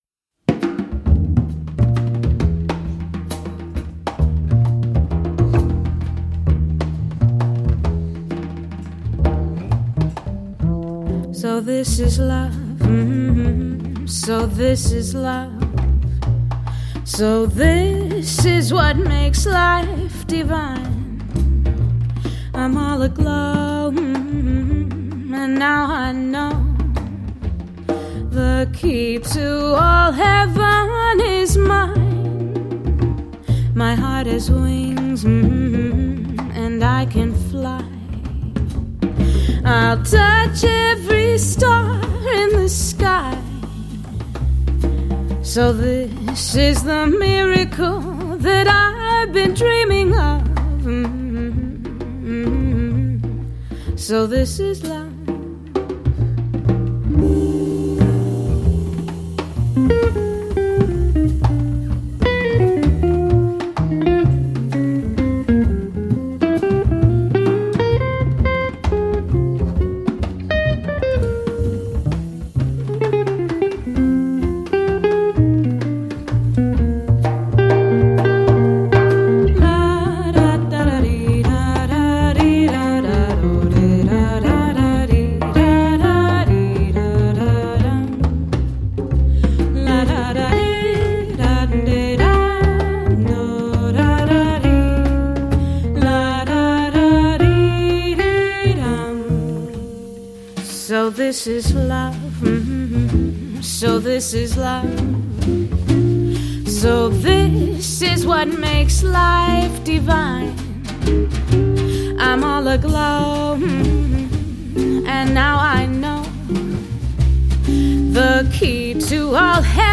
• Guitarister
• sangerinder